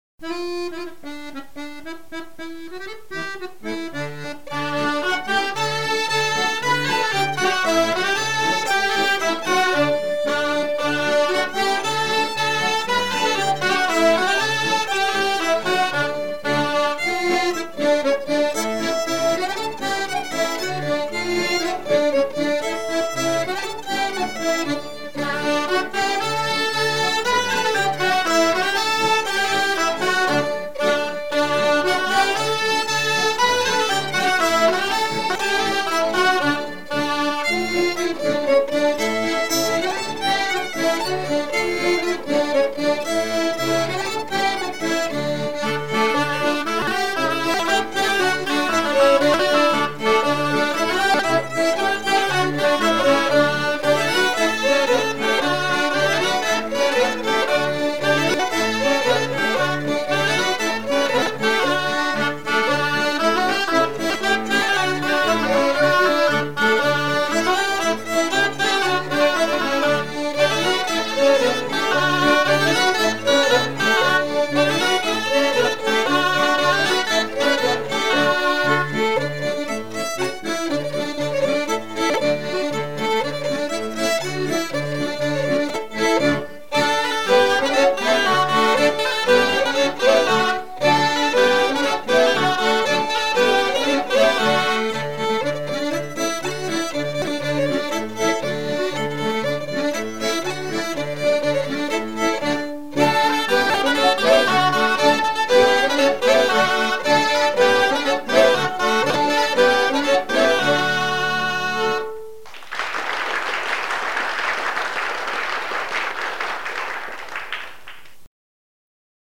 Branle - Sur le cabinet à ma grand-mère
danse : branle : courante, maraîchine ;